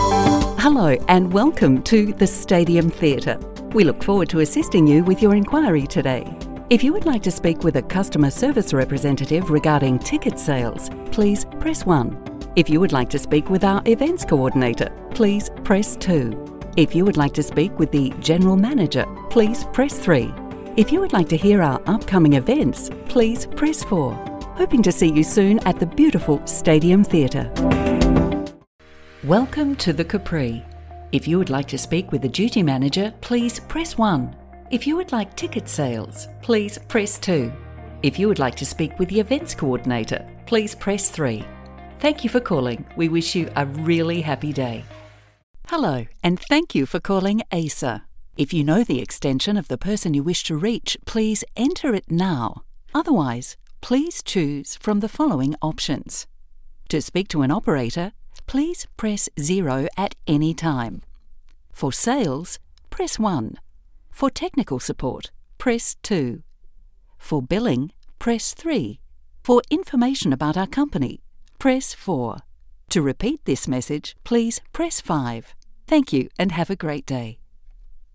Female
English (Australian)
Phone Greetings / On Hold
Words that describe my voice are sensual, conversational, authoritative.
All our voice actors have professional broadcast quality recording studios.